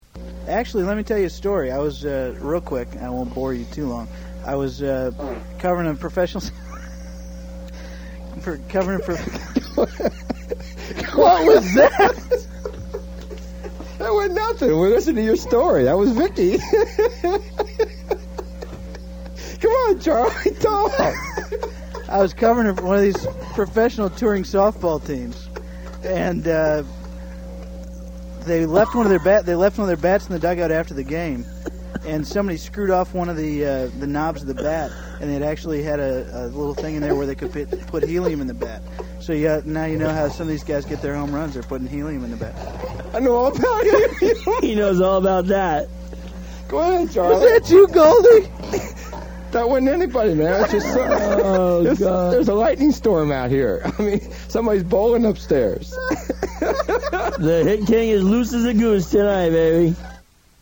Pete passes gas on the air - 1 Minute (pardon the hum)
while doing his radio show out of the MGM Sportsbook.